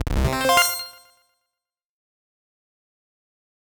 Power-Up Sound Effects
What the title says. 3 slightly different versions.
power_up_sound_v2_0.ogg